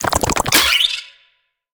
Sfx_creature_penguin_skweak_06.ogg